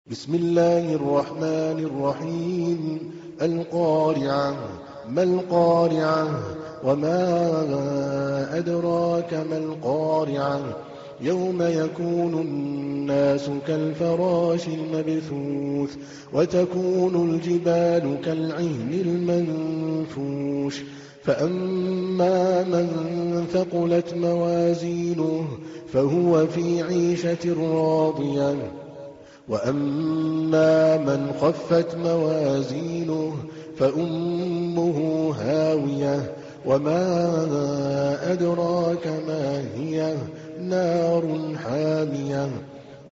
تحميل : 101. سورة القارعة / القارئ عادل الكلباني / القرآن الكريم / موقع يا حسين